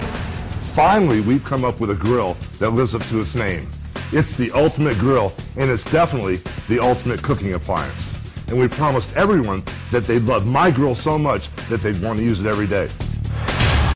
And to be be fair, throughout this entire infomercial, Hulk sounds as though he’s just woken up from a nap,
reading the script in a manner so wooden you’d think they handed him a script, told him to memorize it in two minutes, failed to do so, and then they just threw up cue cards and shot the thing in an hour.